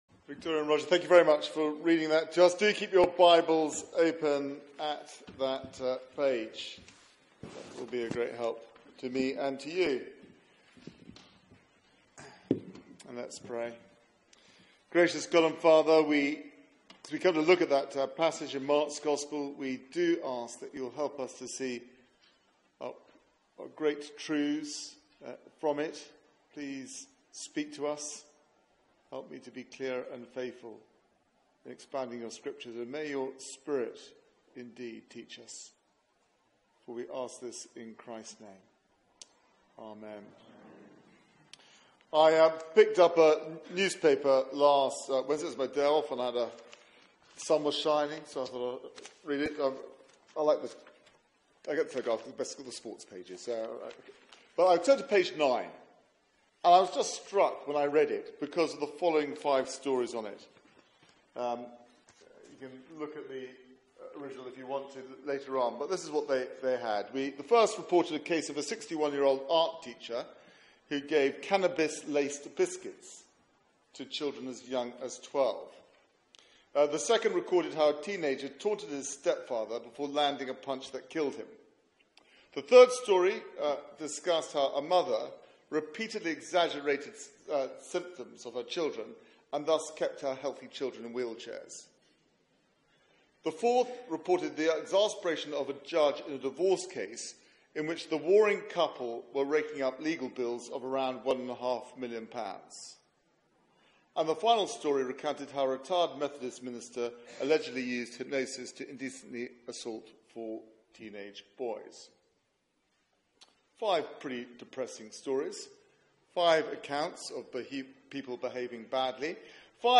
Media for 6:30pm Service on Sun 14th May 2017 18:30 Speaker
Questioning the King Theme: What's your authority? Sermon Search the media library There are recordings here going back several years.